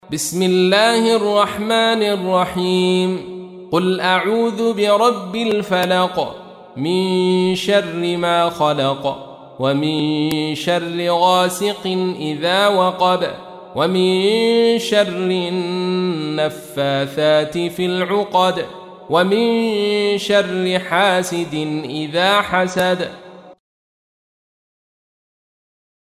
تحميل : 113. سورة الفلق / القارئ عبد الرشيد صوفي / القرآن الكريم / موقع يا حسين